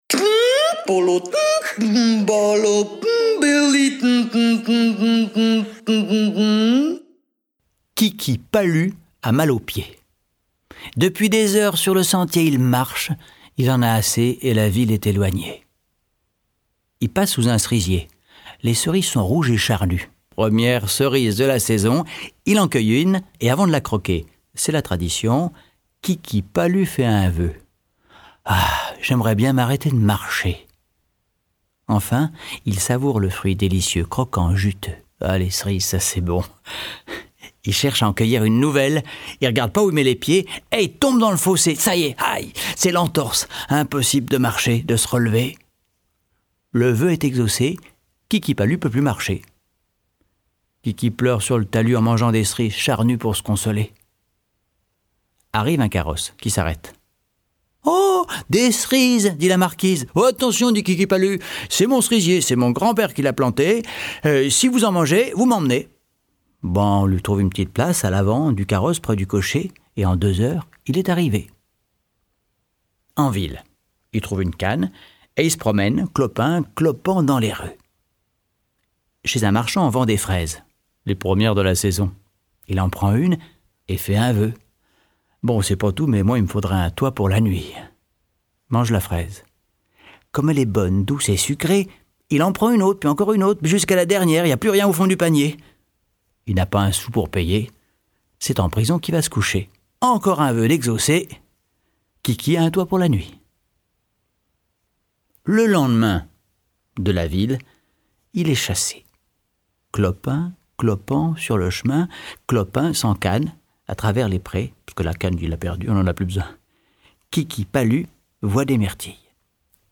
2021-03-05 Pomme, poire, grenade, abricot…Tous les fruits dans un filet de voix… Voix de conteuses gourmandes et de conteurs maraudeurs qui sont allés cueillir des histoires savoureuses dans les vergers du monde ou au coin de leur jardin le plus secret, à l’ombre des arbres de vie.